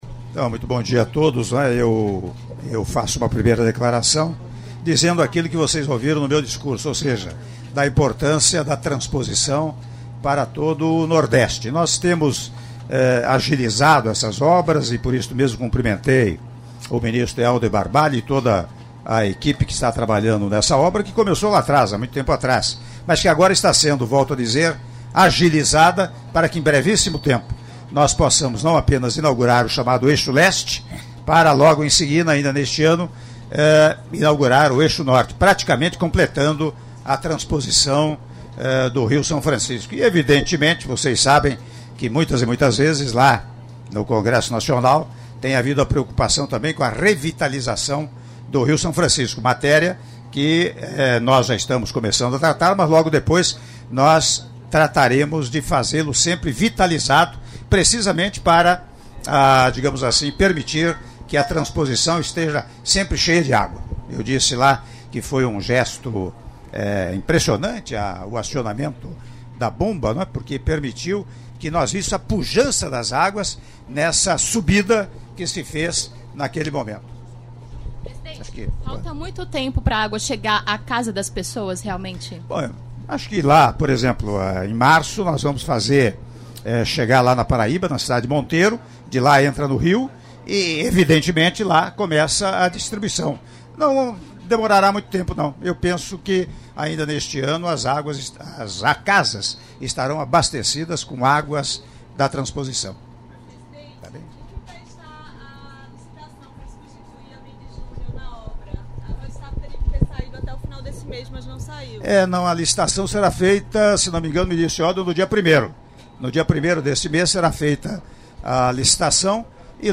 Áudio da entrevista do presidente da República, Michel Temer, após cerimônia de Inauguração da Estação de Bombeamento EBV-3 do Eixo Leste do PISF e Assinatura da Ordem de Serviço para elaboração do Projeto do Ramal do Agreste Pernambucano - Floresta/PE - (03min29s)